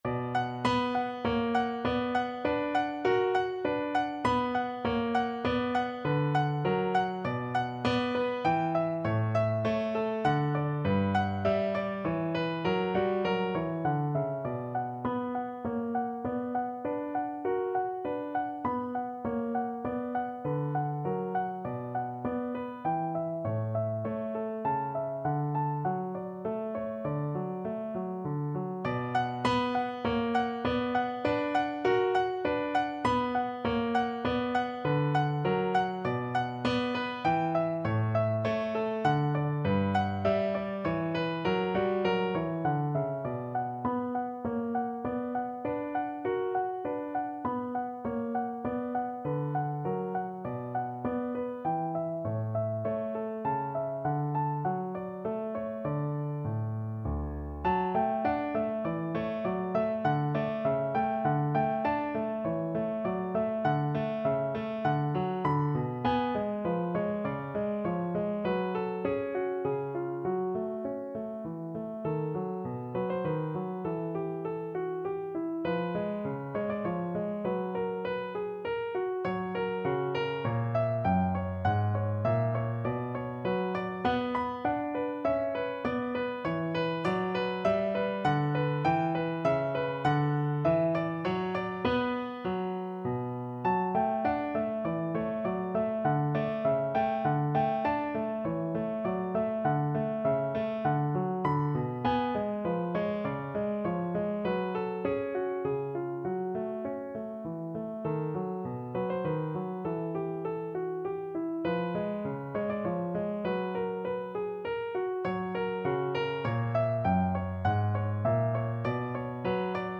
No parts available for this pieces as it is for solo piano.
3/4 (View more 3/4 Music)
Piano  (View more Easy Piano Music)
Classical (View more Classical Piano Music)